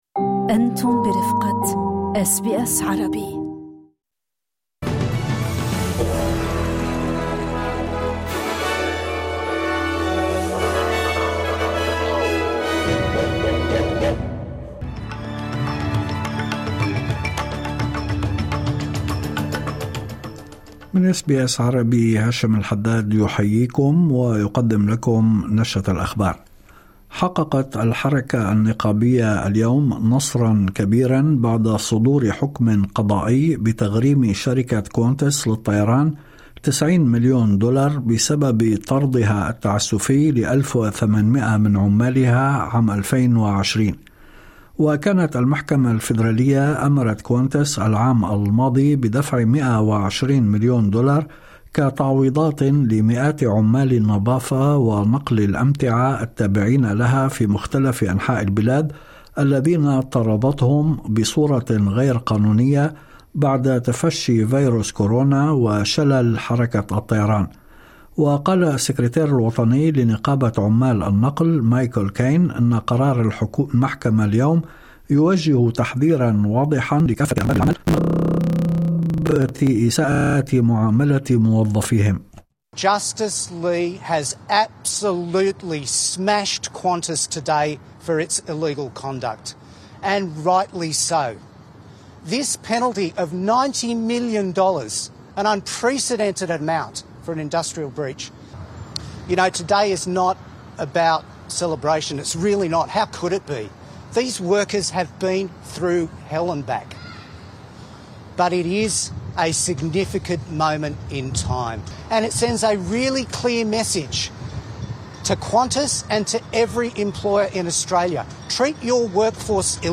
نشرة أخبار الظهيرة 18/08/2025